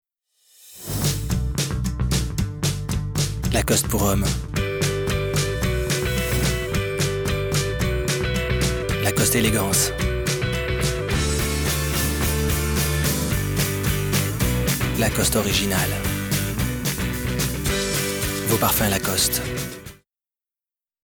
demo voix mp3